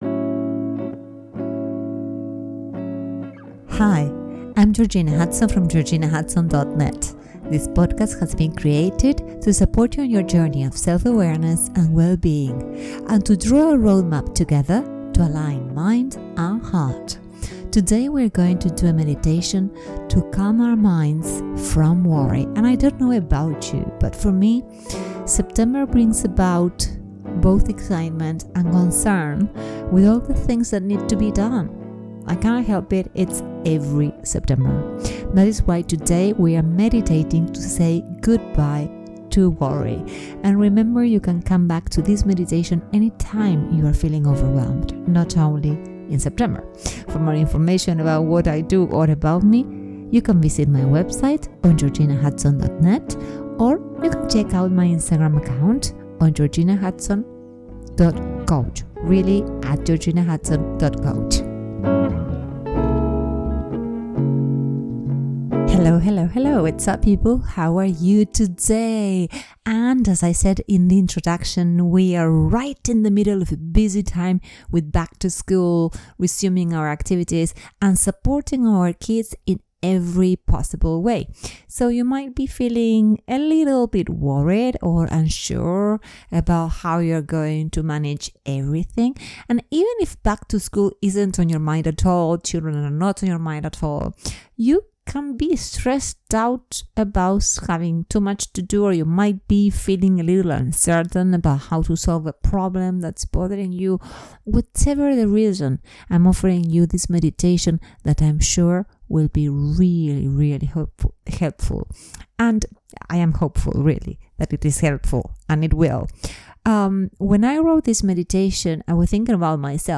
➡Say Bye To Worry With This Guided Meditation